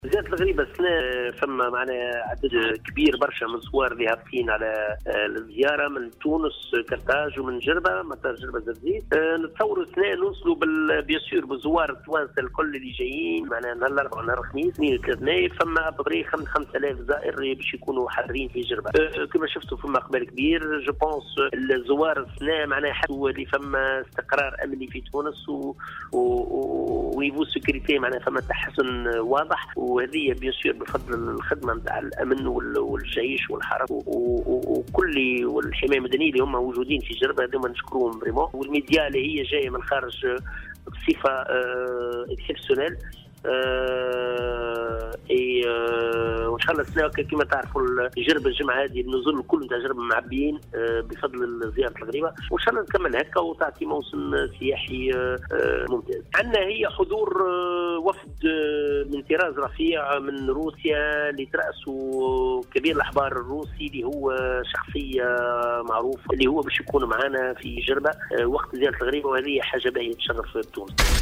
قال منظم رحلات زيارة الغريبة في جربة، روني الطرابلسي في تصريح اليوم لمراسلة "الجوهرة أف أم" إنه من المنتظر أن تستقبل الجهة حوالي 5 آلاف زائر من مختلف الدول.